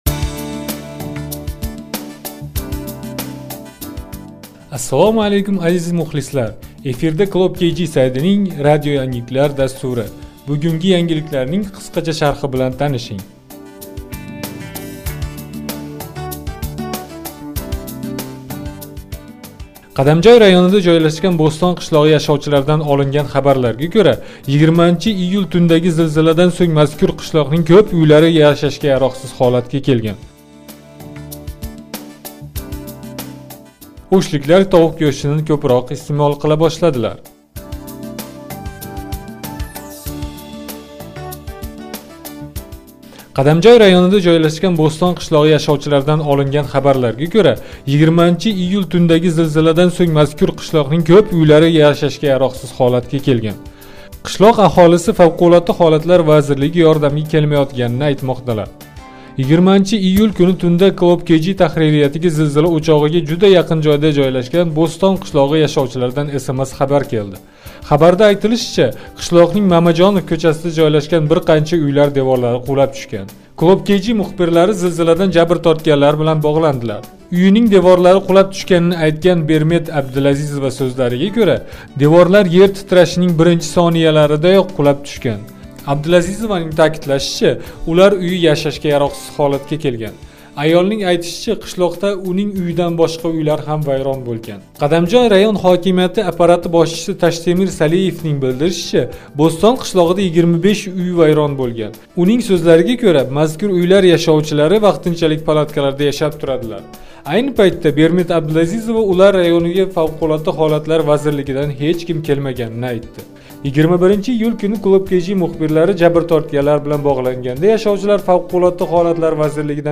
KlOOP.KGнинг онлайн радио янгиликлари